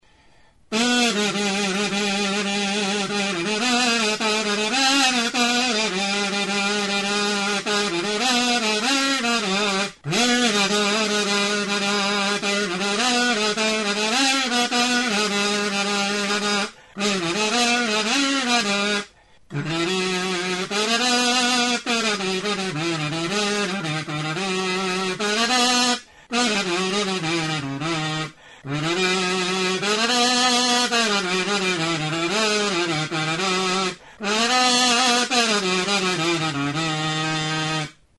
Instrumentos de músicaTURUTA
Membranófonos -> Mirliton
Grabado con este instrumento.
Plastiko berdezko tutu rektangularra da.